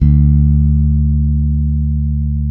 -MM JAZZ D.3.wav